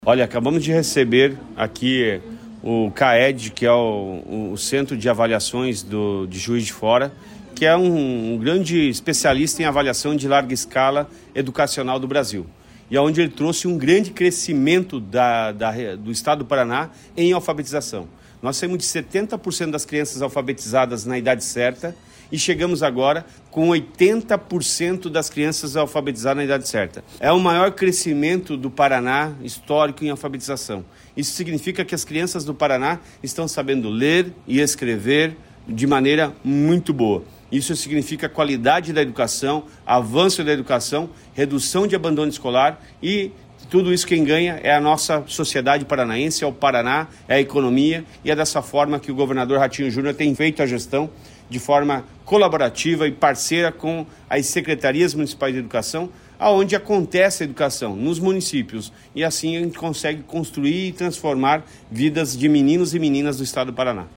Sonora do secretário da Educação, Roni Miranda, sobre o Paraná ter alcançado 80% de crianças alfabetizadas na idade certa